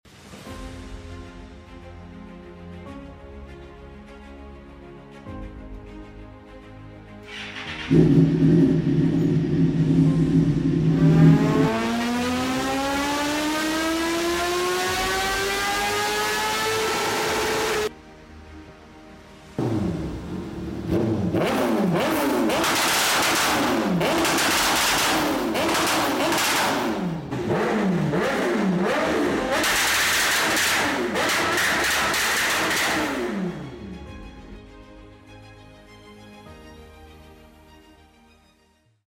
💥Suzuki GSX R 1000💥 ✅Aplicação do sound effects free download
💥Suzuki GSX-R 1000💥 ✅Aplicação do Kit 2-Step